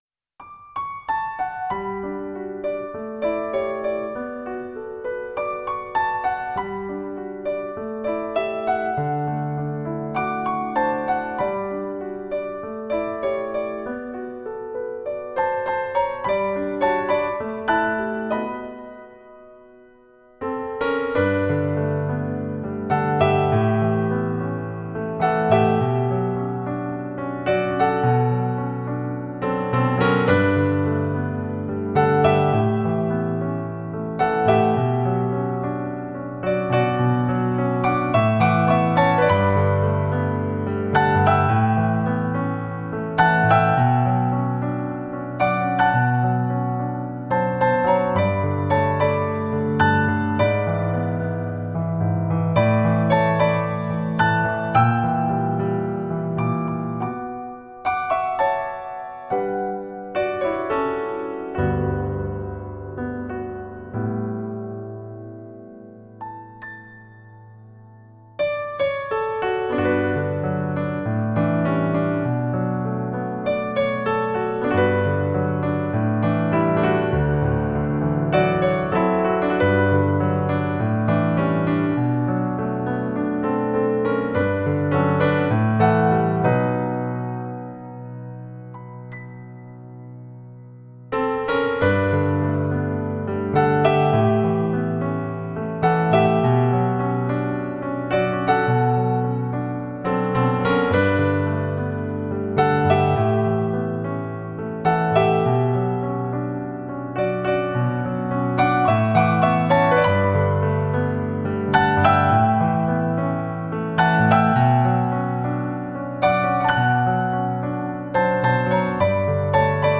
【ピアノソロ】